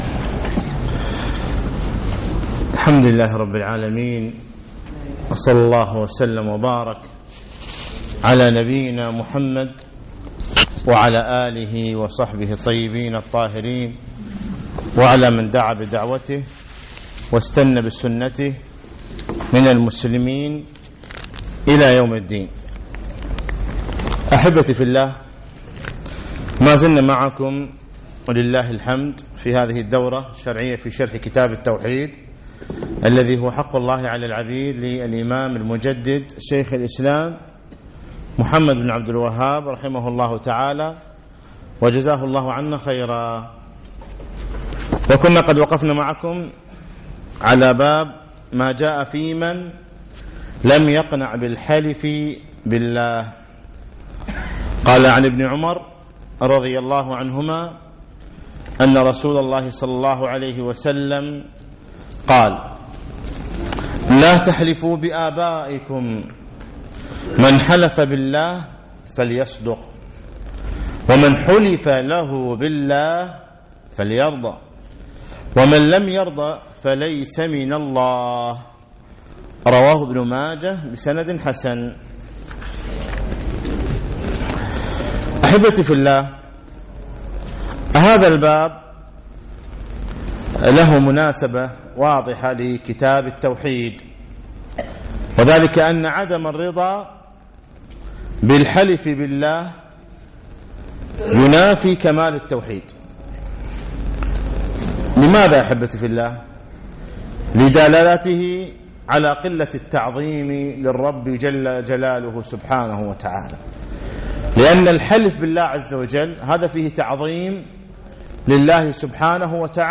الدرس السادس والعشرون